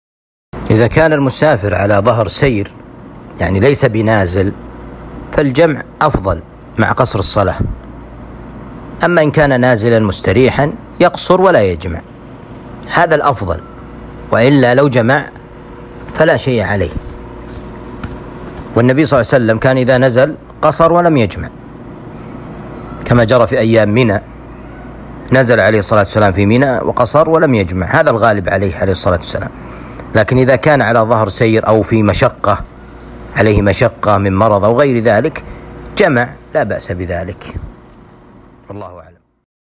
ما هو الأفضل للمسافر الجمع أم القصر أسئلة لقاء اليوم المفتوح